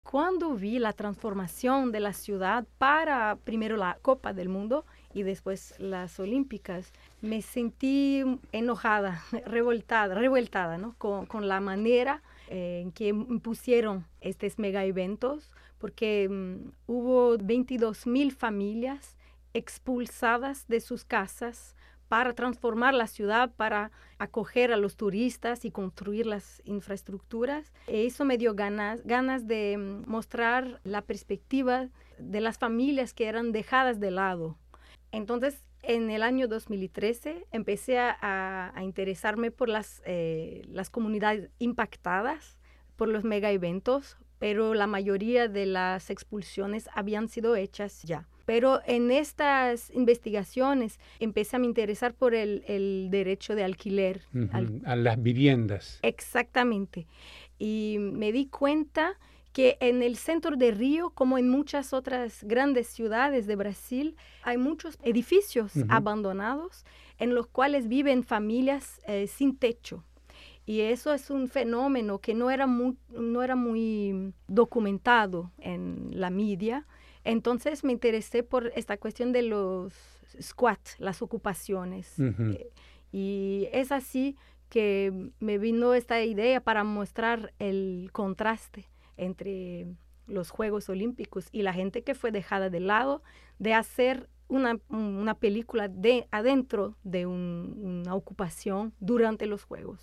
Al momento de hacer esta entrevista ya la película estaba siendo presentada en salas.